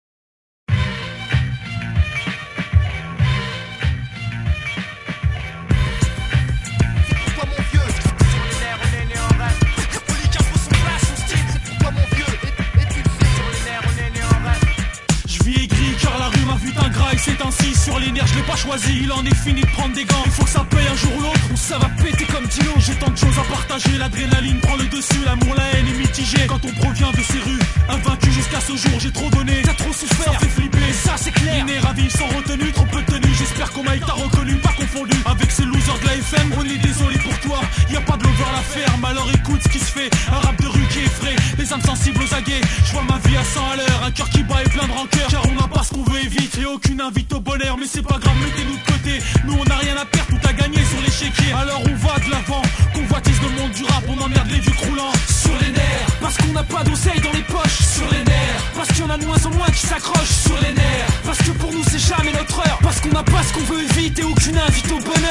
Trois chanteurs